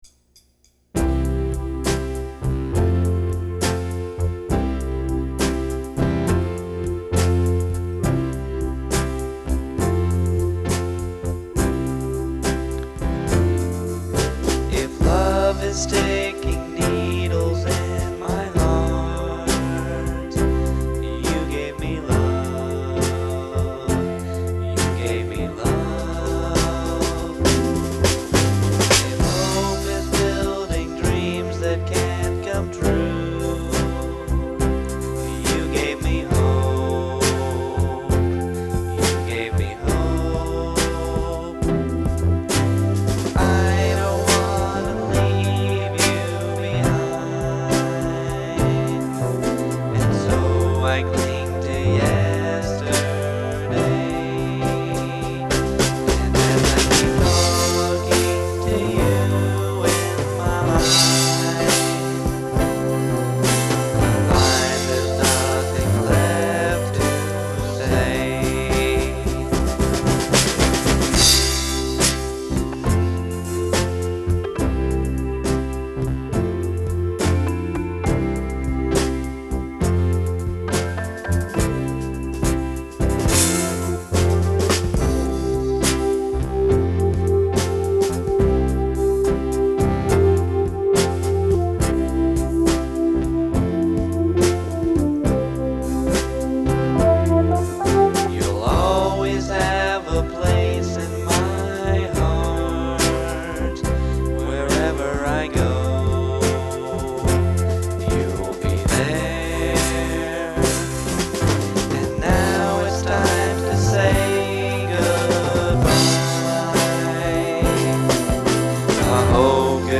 sincere ballad